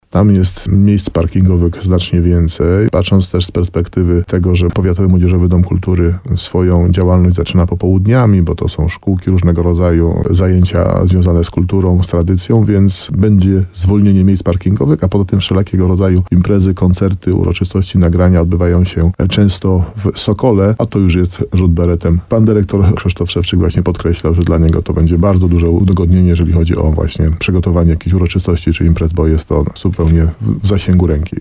– To dla wszystkich będzie ogromne ułatwienie – komentuje starosta nowosądecki Tadeusz Zaremba.